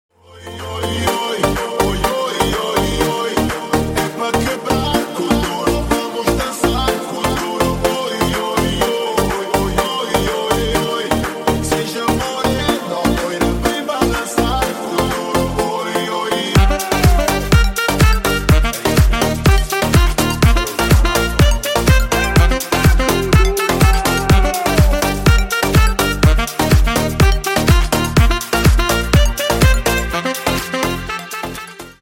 Клубные Рингтоны » # Латинские Рингтоны
Танцевальные Рингтоны